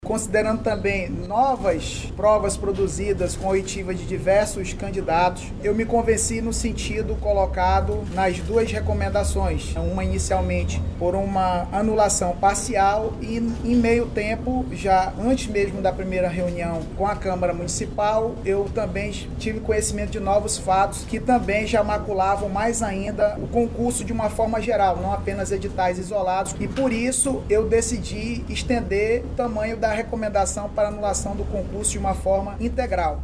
Mas, durante reuniões realizadas no decorrer desta semana, com os vereadores, foram identificadas novas irregularidades que levaram ao pedido da anulação completa do concurso, explica o promotor de Justiça, Armando Gurgel.